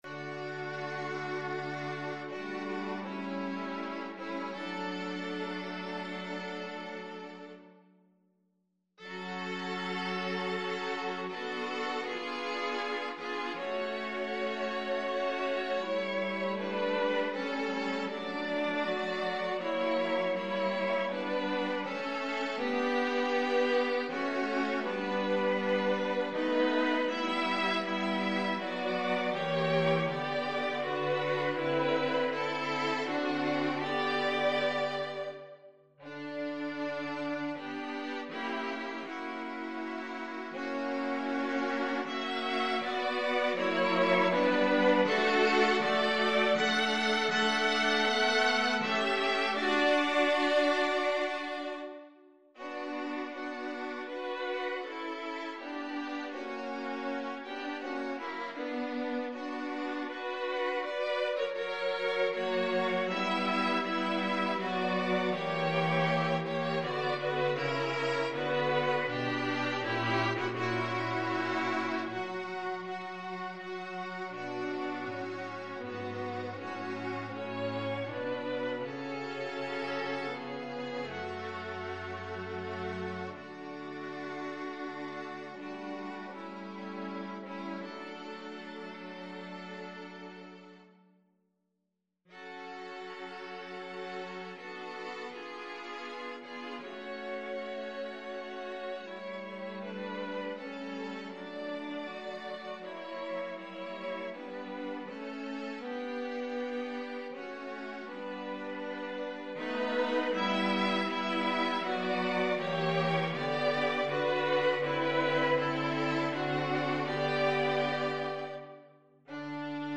Free Sheet music for String Quartet
Violin 1Violin 2ViolaCello
D major (Sounding Pitch) (View more D major Music for String Quartet )
3/4 (View more 3/4 Music)
~ = 80 Andante ma non lento
Classical (View more Classical String Quartet Music)